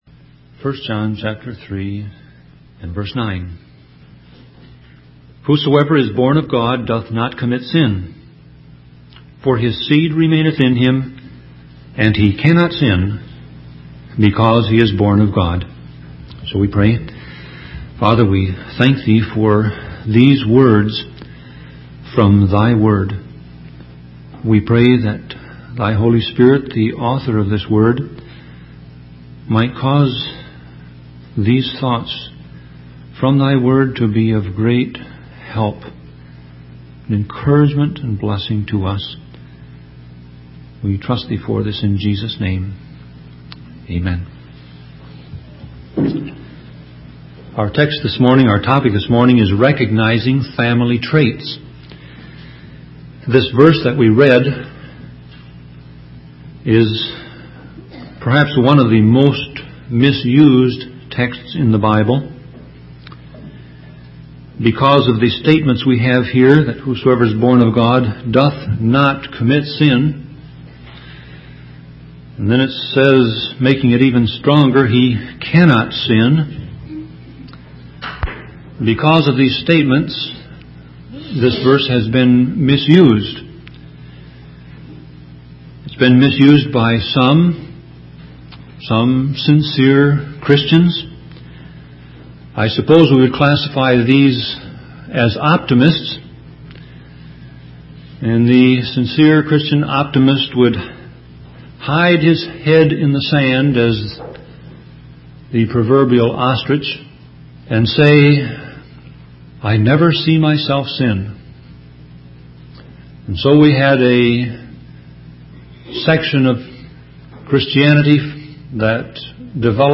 Sermon Audio Passage: 1 John 3:9 Service Type